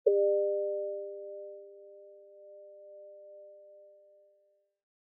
以下は私がギターをエフェクトなしで、そのままSound Forge Pro 13でサンプリングしたWAVファイルです。
ここで500Hzを指定して、その倍音を抽出してみた結果、こんな音になりました。
なんか鐘の音っぽいというか、不思議なサウンドになりますよね。
Guitar500.wav